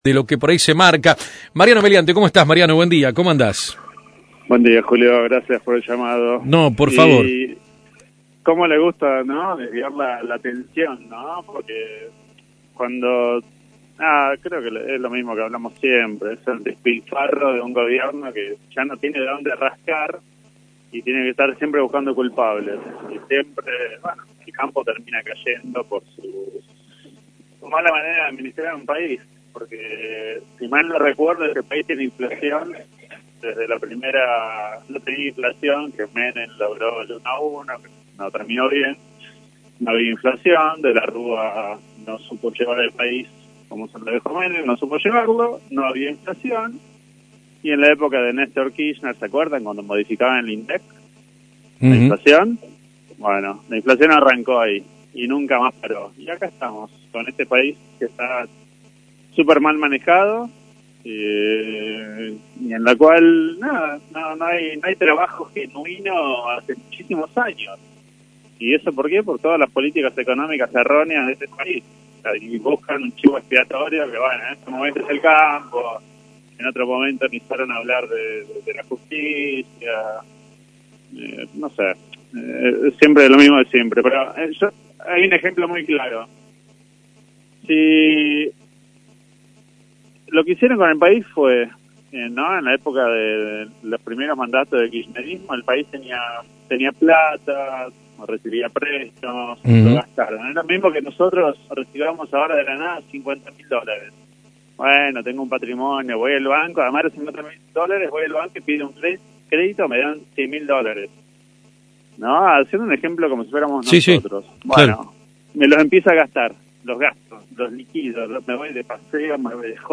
Integrante de la sociedad Rural, dialogó en AM 1210 sobre la situación del conflicto campo-Gobierno y las críticas recibidas por el sector: